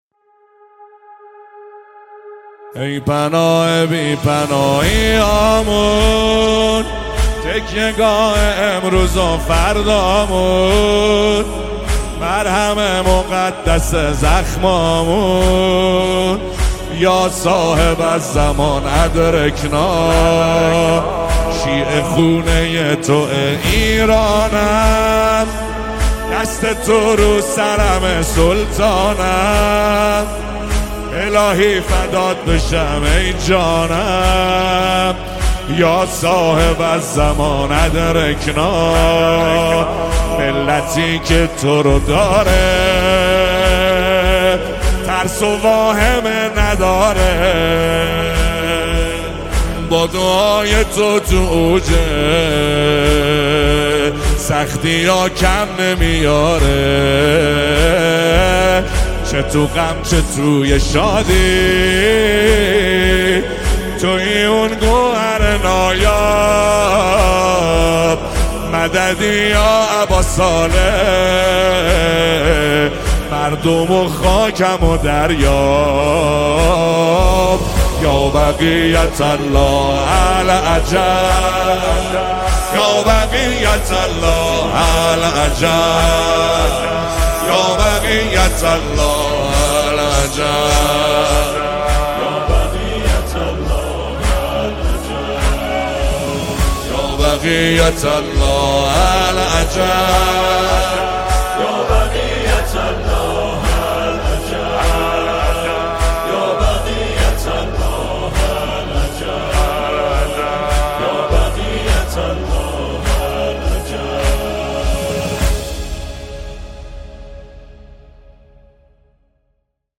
دانلود نماهنگ و مناجات مهدوی
با نوای دلنشین